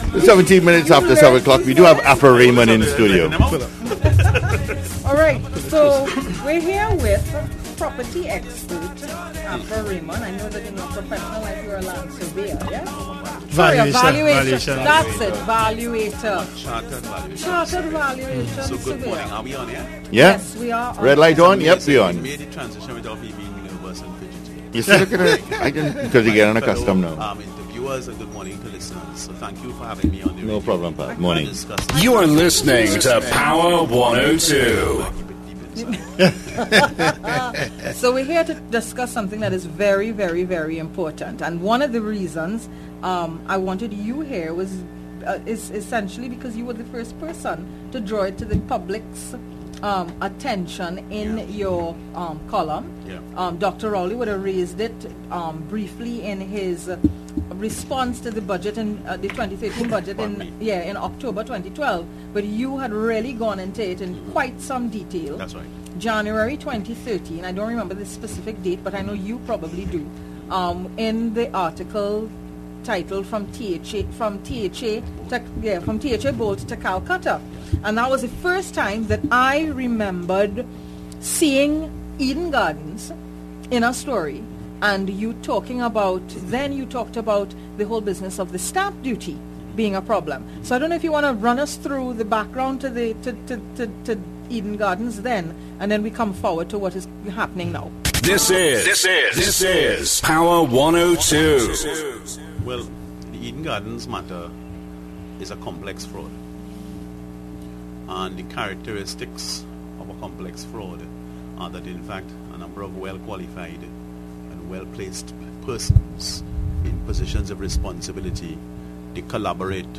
AUDIO: The Showdown Show Interview, i95.5FM – 7 June 2015